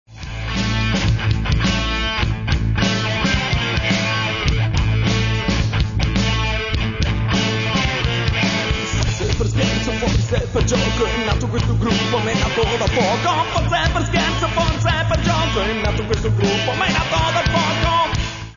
voce
chitarra
basso e cori
batteria e cori
Le canzoni sono state registrate e mixate nel